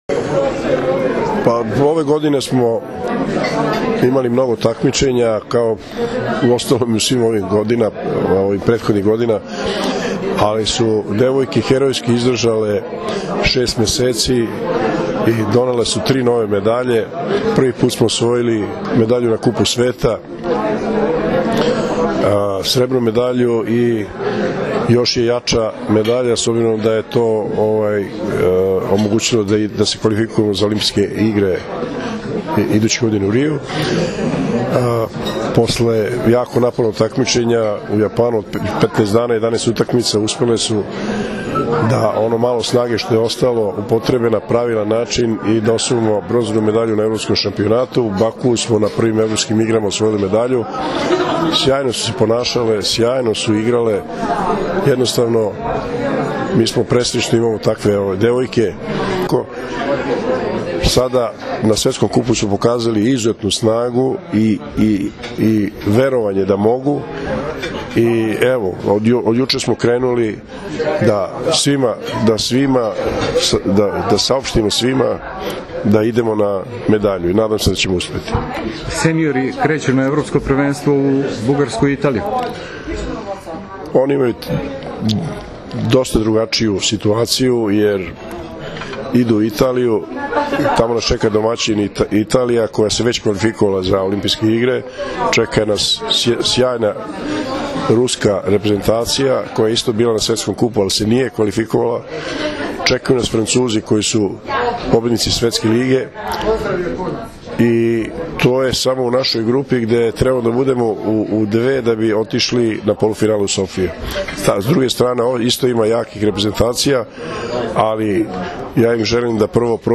Odbojkašice su zatim produžile u beogradski hotel „M“, gde im je priređen svečani doček.
IZJAVA